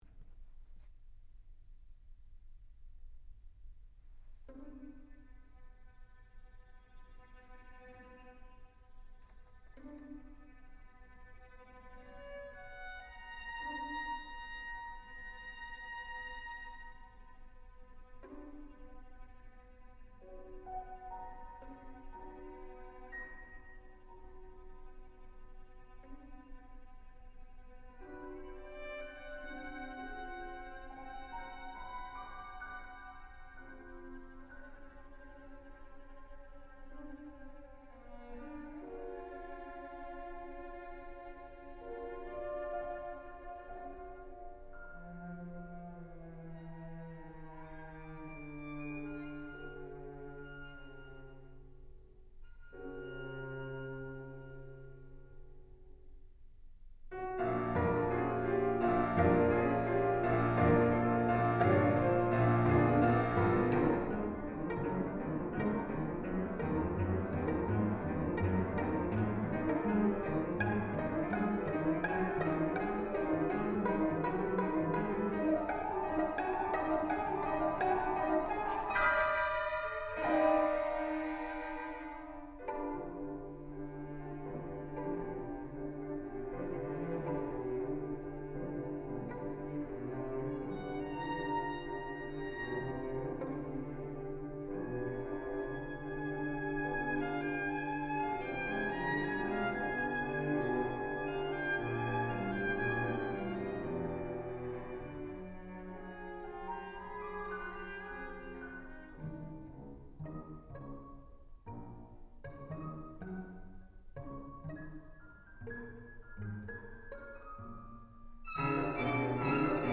Live opname